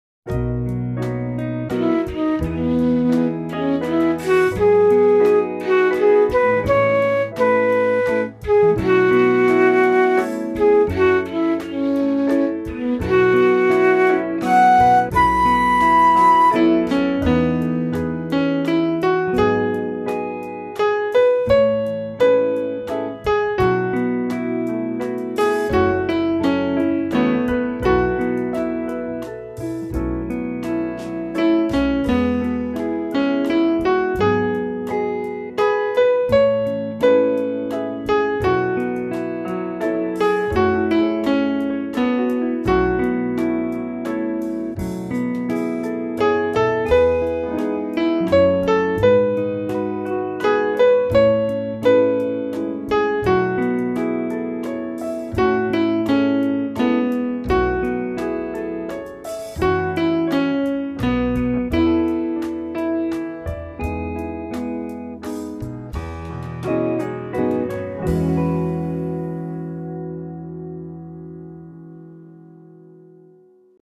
This is a reverent litany in a restrained waltz time.